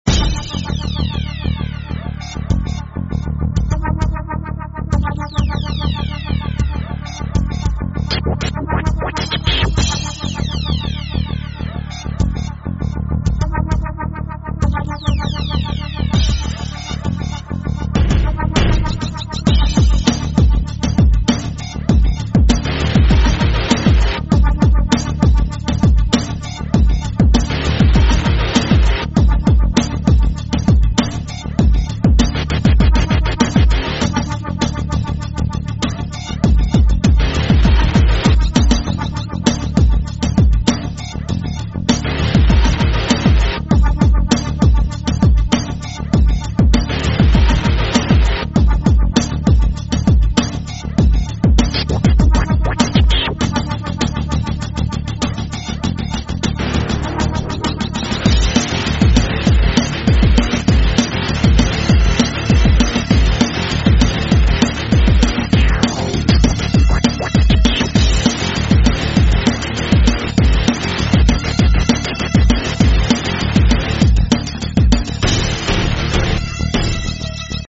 Estilo: Hip Hop